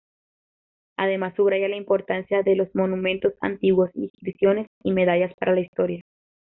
im‧por‧tan‧cia
/impoɾˈtanθja/